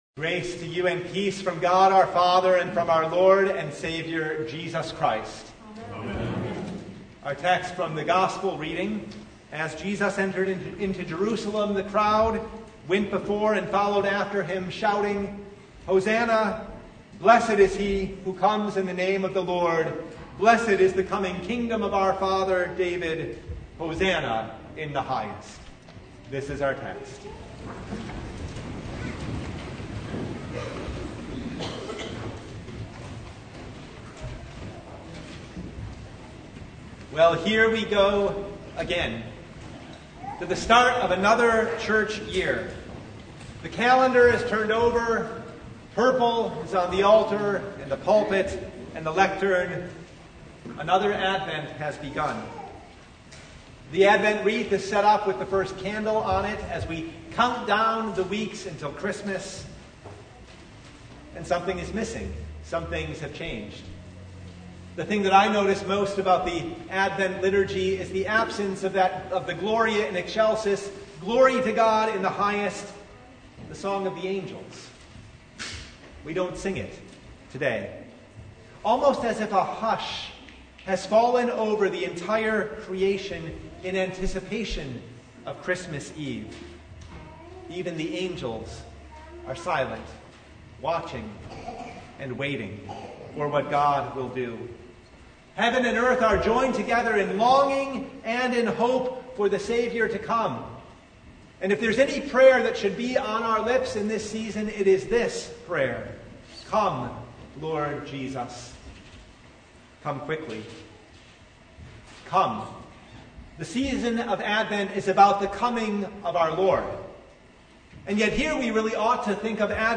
Sermon from First Sunday in Advent (2023)